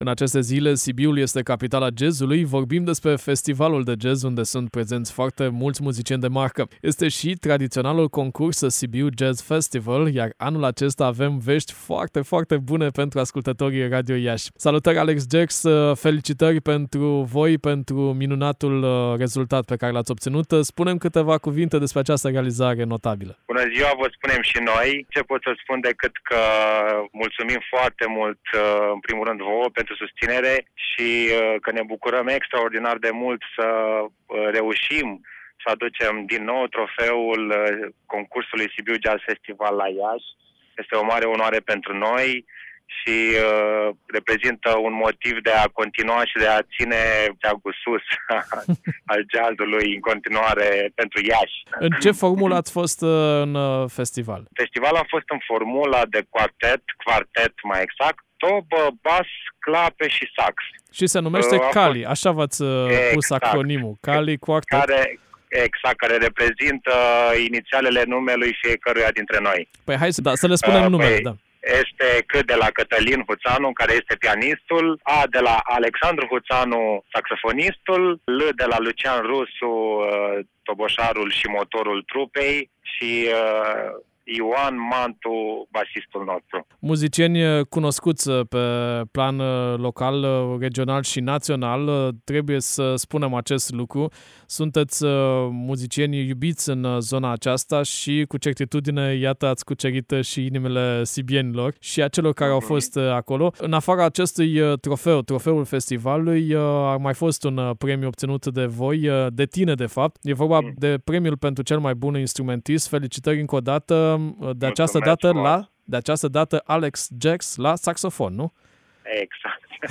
(Interviu/EXCLUSIV)